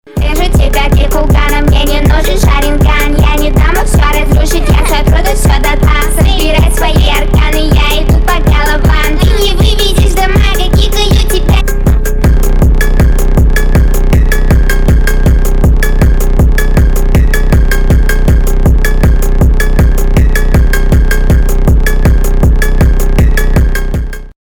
рэп
фонк